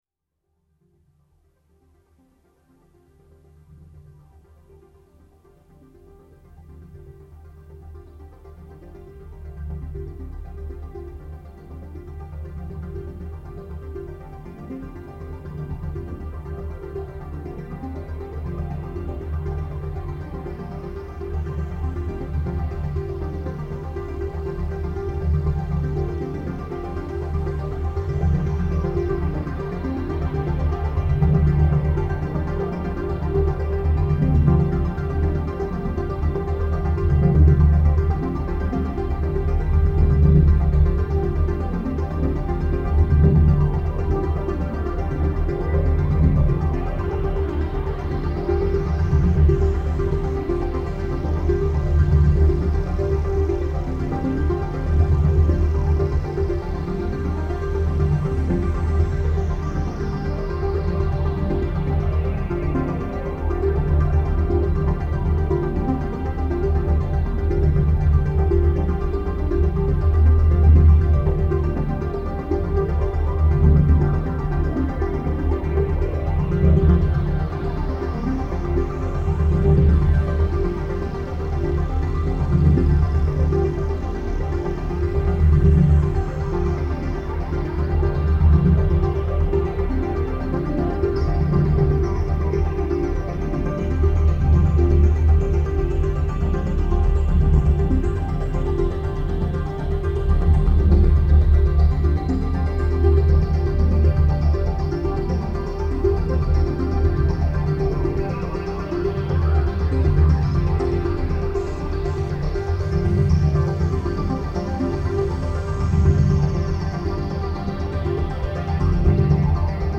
Throwback Thursday: High School MIDI Project
Sometime in the late ’80s, while still figuring out how to sync up my MIDI studio, a friend and I made an amazing discovery: we could use a drum machine to drive the synthesizers!
Made with a Roland Juno 106, a Yamaha DX-7, a Yamaha drum machine (don’t remember which model), and a Tascam 4 track.